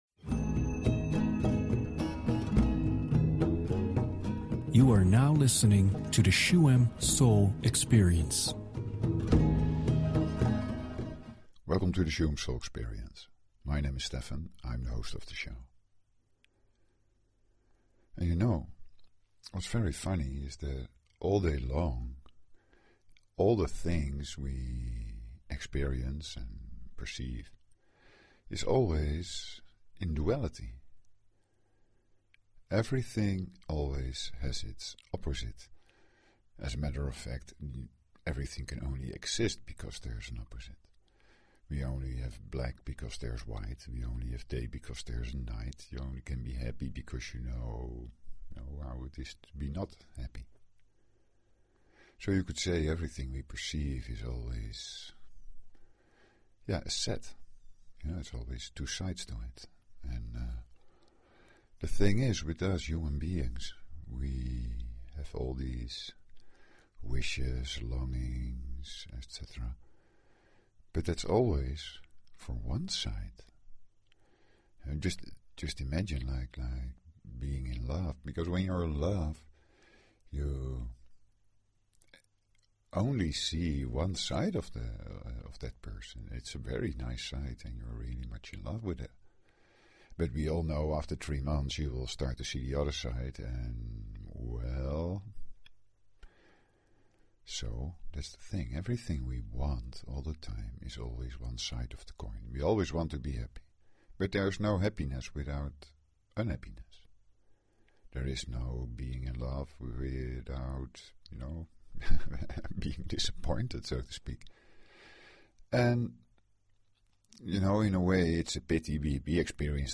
Talk Show Episode, Audio Podcast, Shuem_Soul_Experience and Courtesy of BBS Radio on , show guests , about , categorized as
For the meditation, it is best to listen through headphones, sit or lie down and take your time.